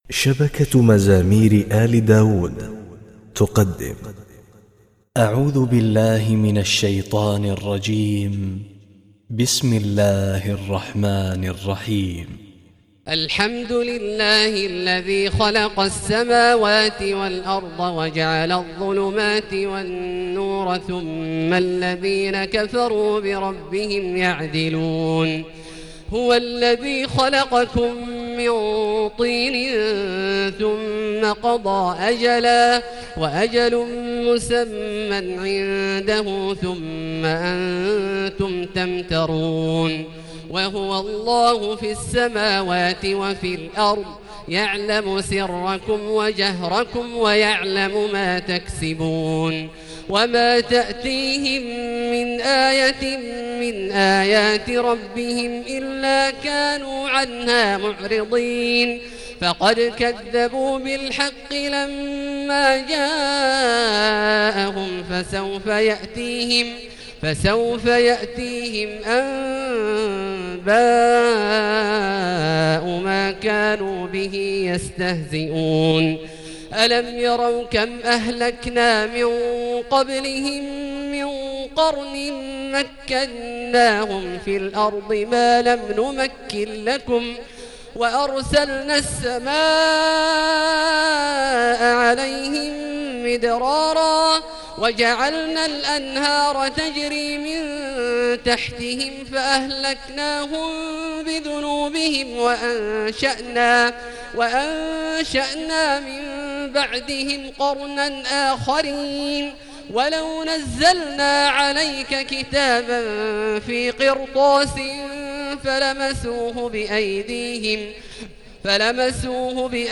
تهجد الحرم المكي 1439 هـ - شبكة مزامير آل داوُد القرآنية